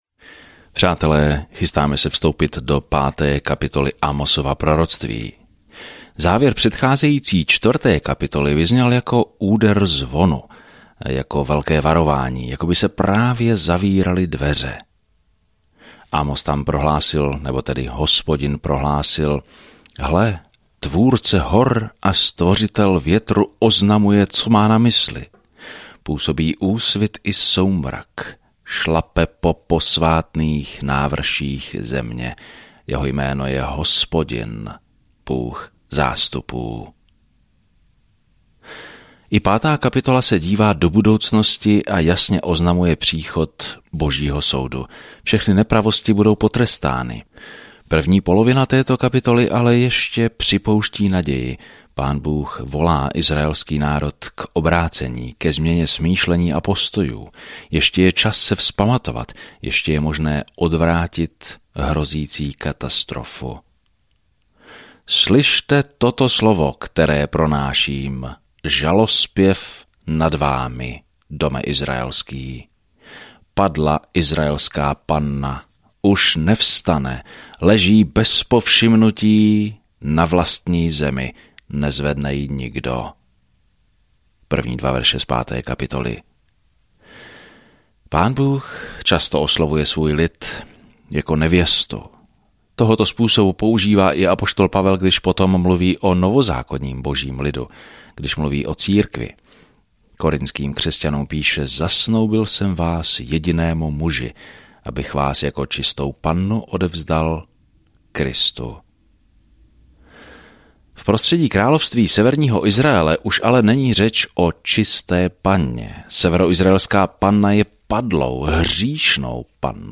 Písmo Amos 5:1-13 Den 6 Začít tento plán Den 8 O tomto plánu Amos, venkovský kazatel, jde do velkého města a odsuzuje jejich hříšné způsoby a říká, že všichni jsme zodpovědní Bohu podle světla, které nám dal. Denně procházejte Amosem a poslouchejte audiostudii a čtěte vybrané verše z Božího slova.